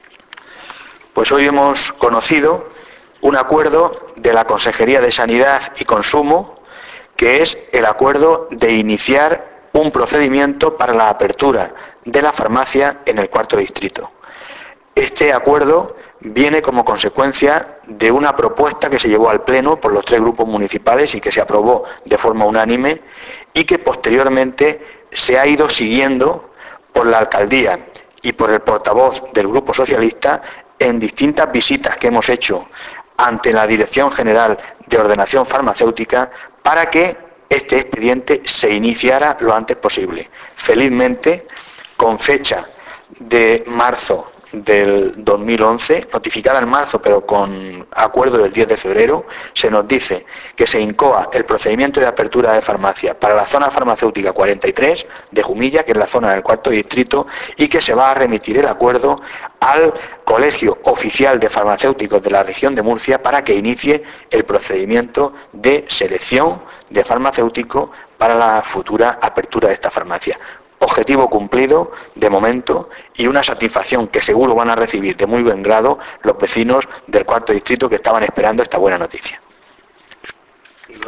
Descargar: El alcalde habla del inicio de este procedimiento subir